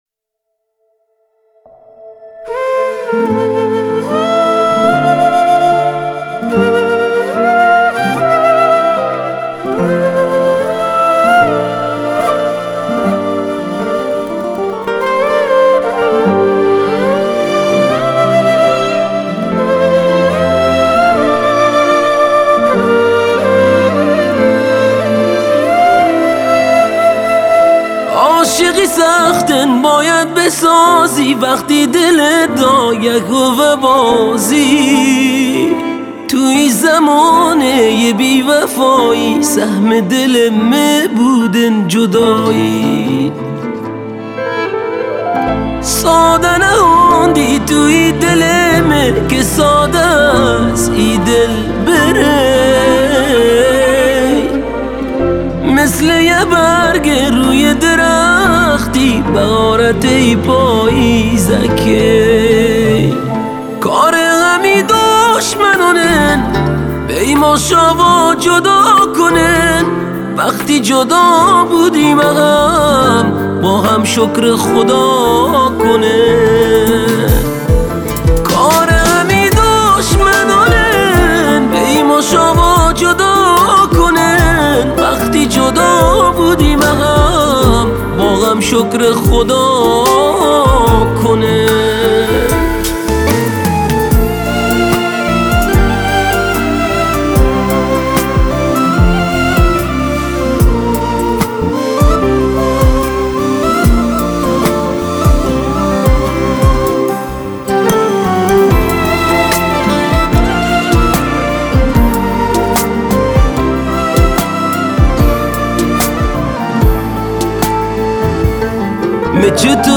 بندری